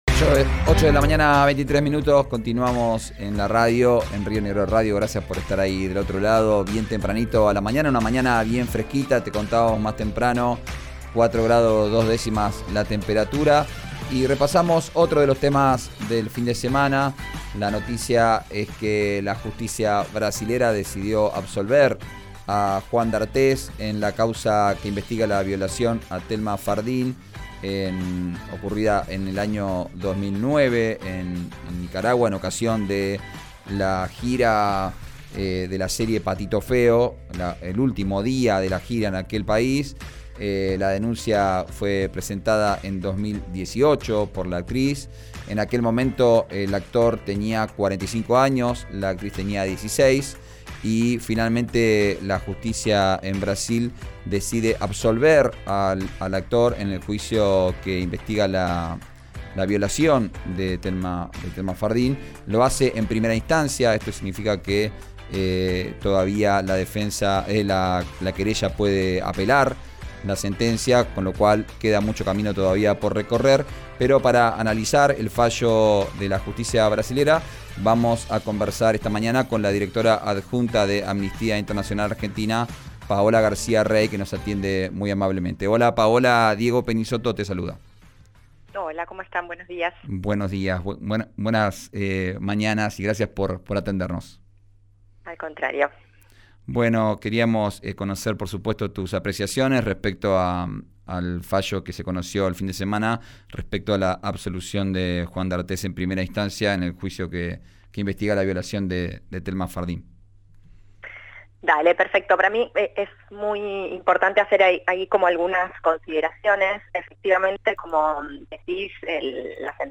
En conferencia de prensa, la actriz cuestionó a la Justicia brasileña y aseguró que el abuso sexual se probó.